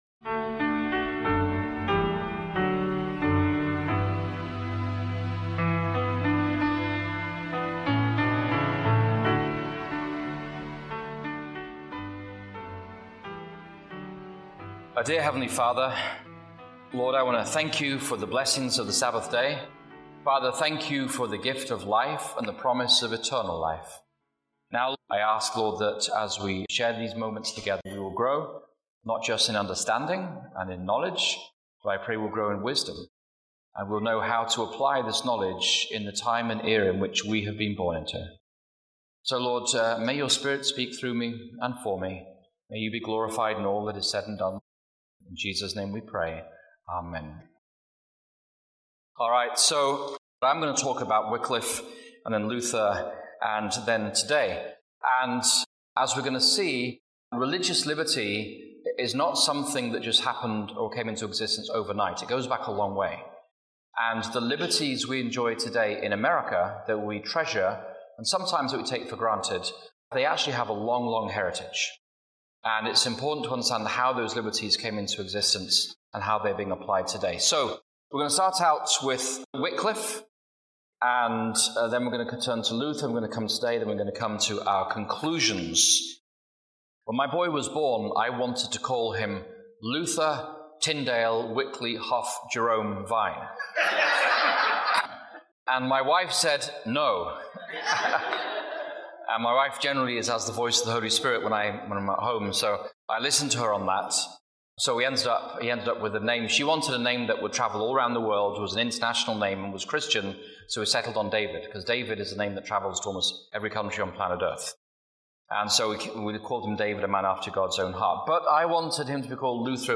This sermon traces the fight for religious liberty from Wycliffe and Luther to the digital age, showing how access to Scripture, technology, and freedom of conscience have always challenged concentrated religious power. By drawing powerful historical parallels, it calls believers to embrace personal transformation in Christ and live out a faith guided by Scripture, conscience, and the Holy Spirit.